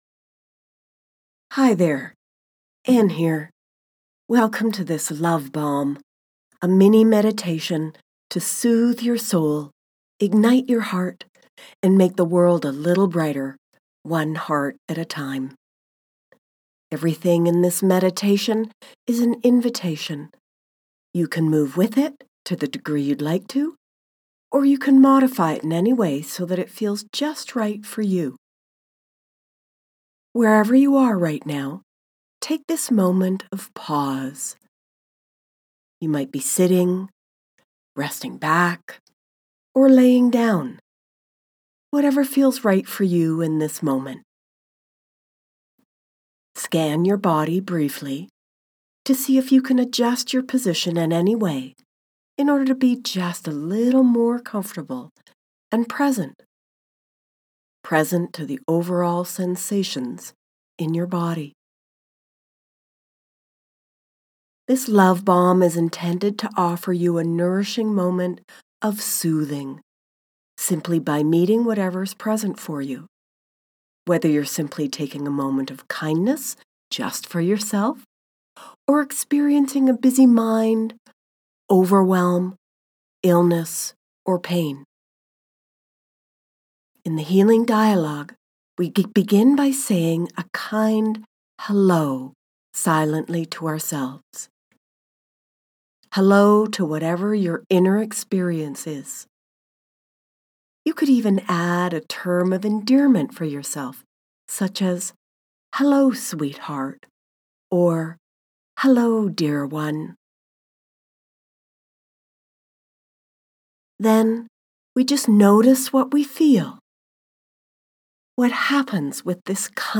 LOVE BALMS – MEDITATIONS TO SOOTHE YOUR SOUL